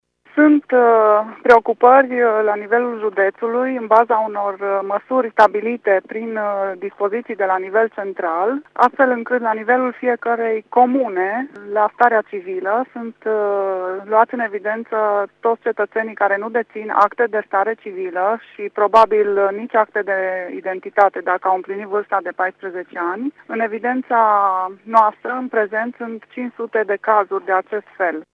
Codruța Sava a mai spus că în județul Mureș, în zonele sărace, mai sunt aproximativ 500 de persoane care încă nu sunt luate în evidență: